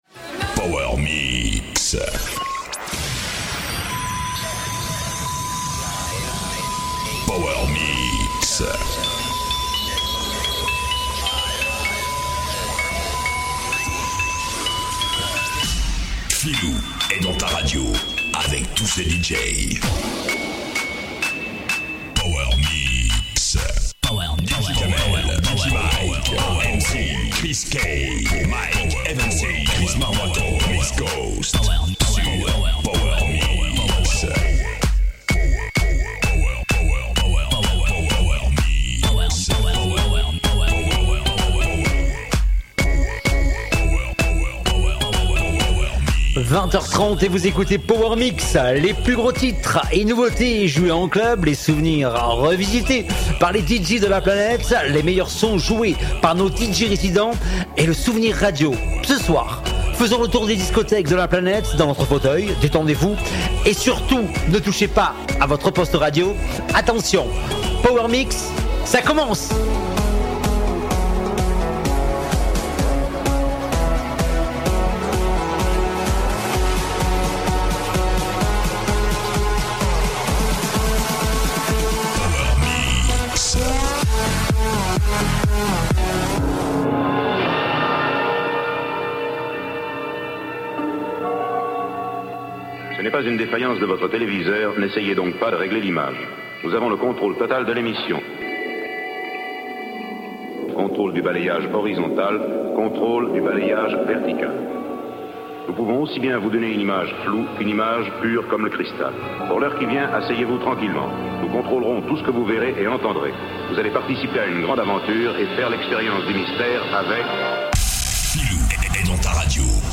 la communauté des DJ's (Men and Women)